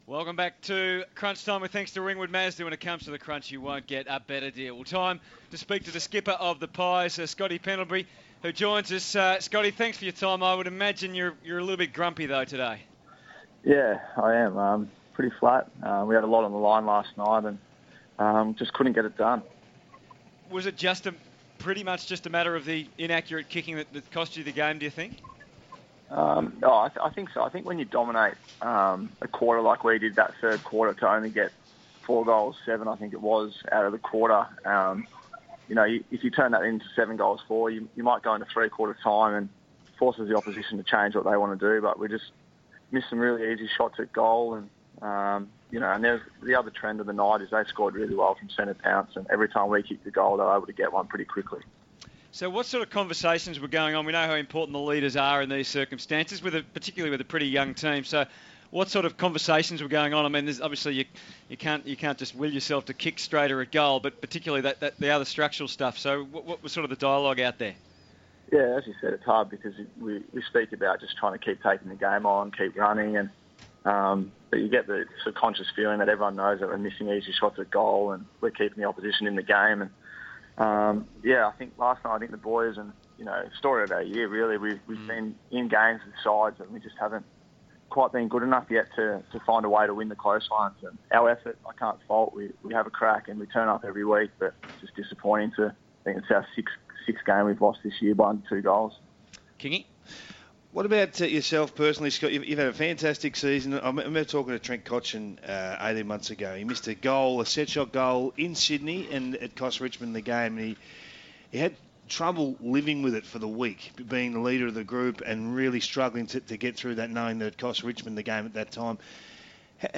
Listen to captain Scott Pendlebury speak with the Crunch Time team on SEN 1116 on Saturday 15 August 2015.